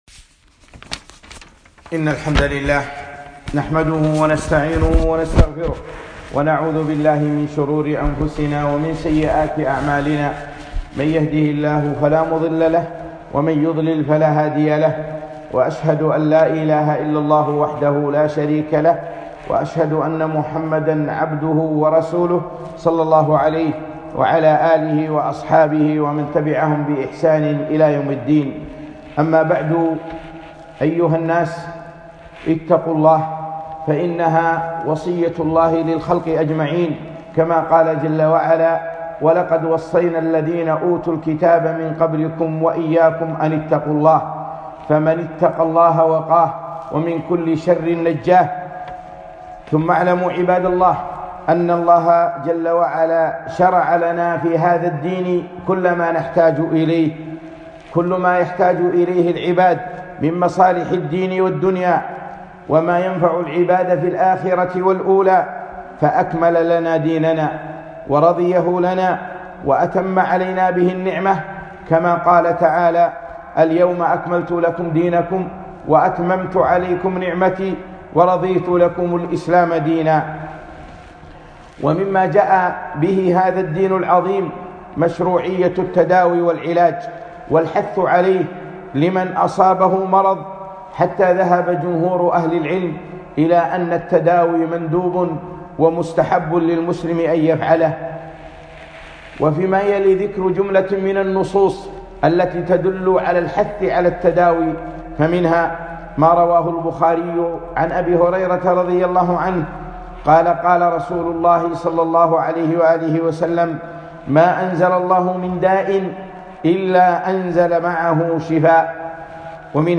خطبة - التداوي والتحذير من عيد الكفار